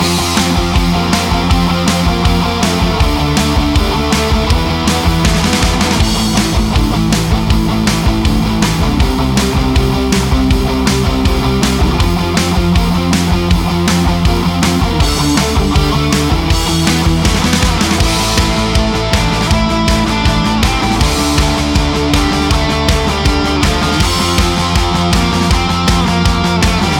вот тут гитара более менее разделена ? (ритм два канала + соло) Вложения Гитара.mp3 Гитара.mp3 597,8 KB · Просмотры: 1.231